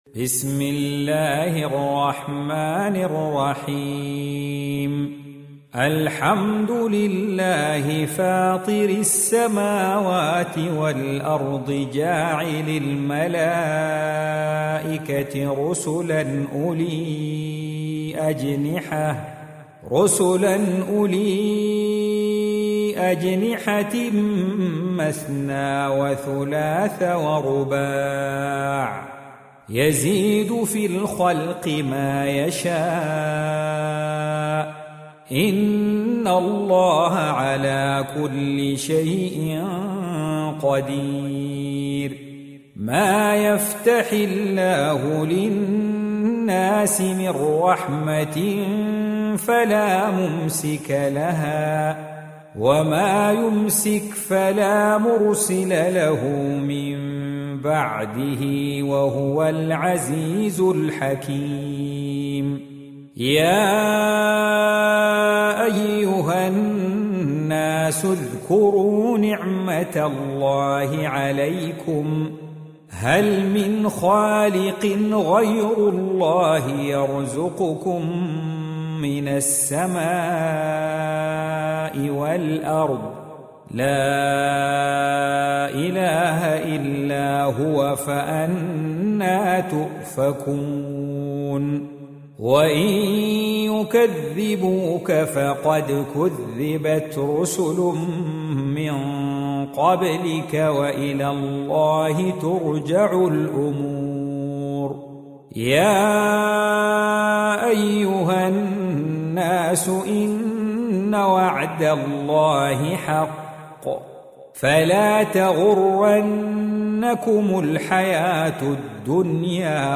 Surah Repeating تكرار السورة Download Surah حمّل السورة Reciting Murattalah Audio for 35. Surah F�tir or Al�Mal�'ikah سورة فاطر N.B *Surah Includes Al-Basmalah Reciters Sequents تتابع التلاوات Reciters Repeats تكرار التلاوات